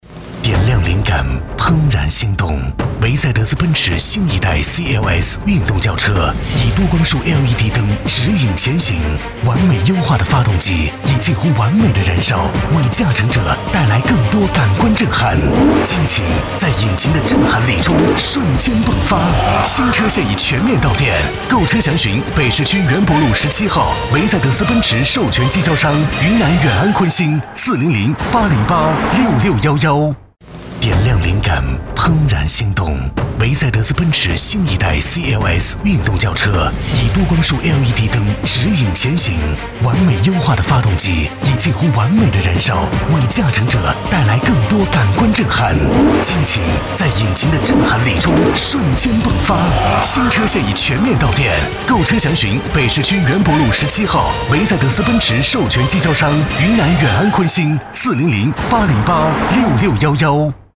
国语青年大气浑厚磁性 、沉稳 、男专题片 、宣传片 、120元/分钟男S347 国语 男声 专题片-人物讲解-沉稳大气 大气浑厚磁性|沉稳